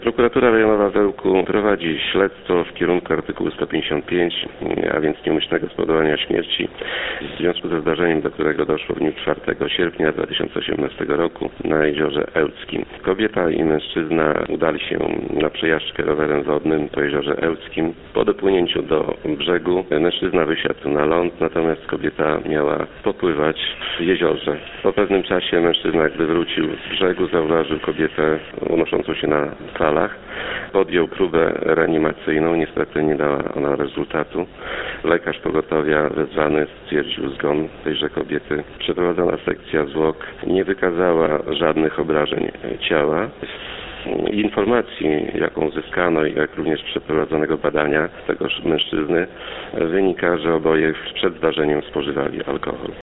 – Z ustaleń śledczych wynika, że oboje spożywali wcześniej alkohol – powiedział Radiu 5 Prokurator Rejonowy w Ełku Jan Mikucki.